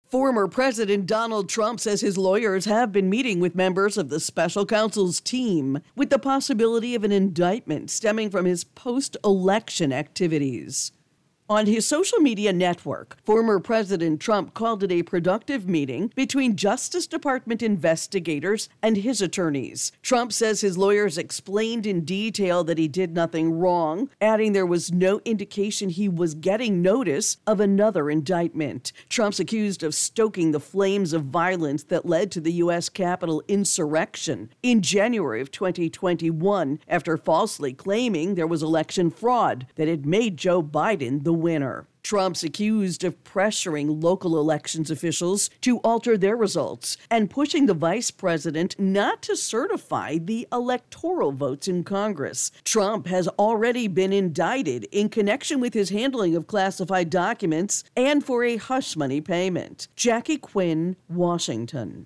Former President Donald Trump says his lawyers have been meeting with members of the special counsel’s team, with the possibility of an indictment stemming from his post-election activities. AP correspondent